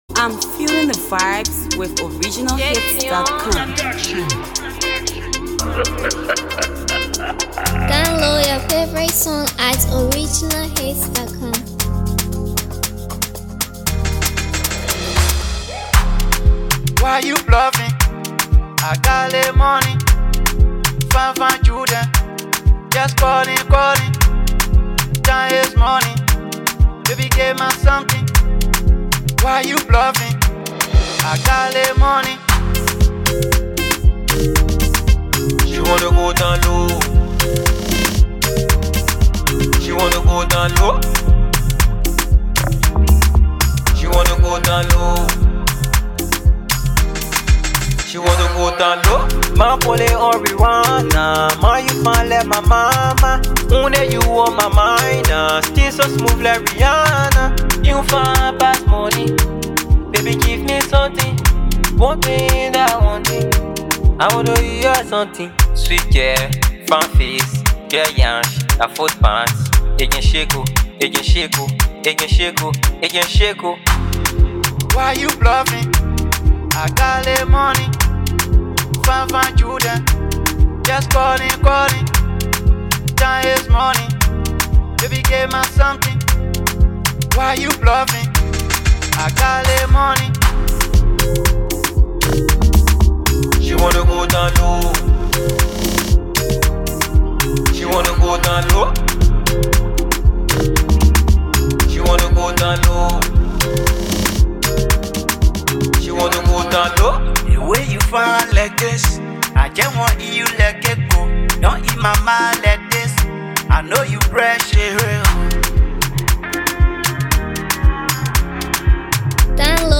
skilled vocals